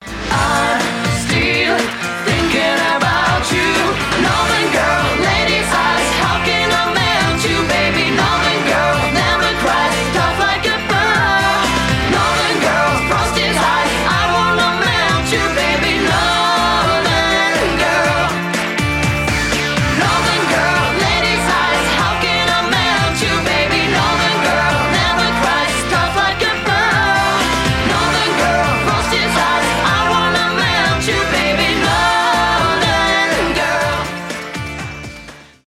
live
поп